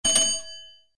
SZ_trolley_bell.ogg